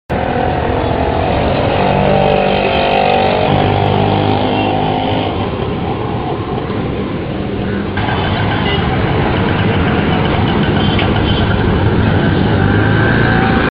auto-rickshaw.mp3